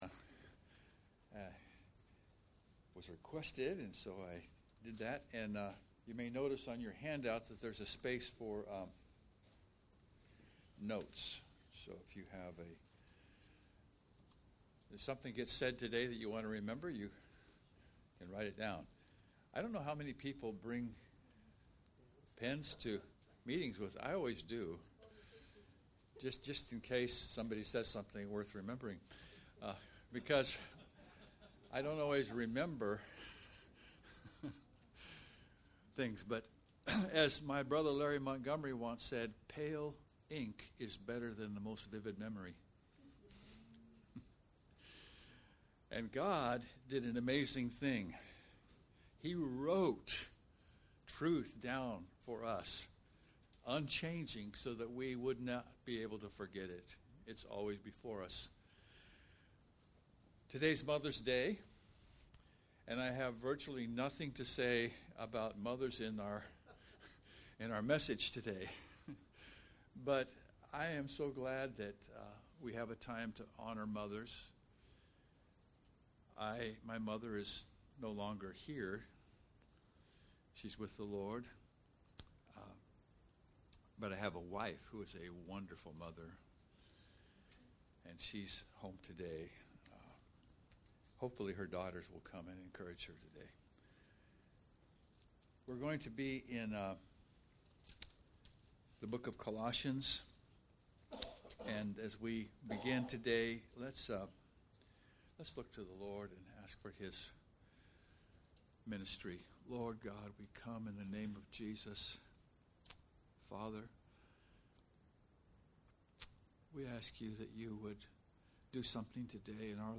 Shadow & Substance Passage: Colossions 2 Service Type: Sunday Morning « Colossions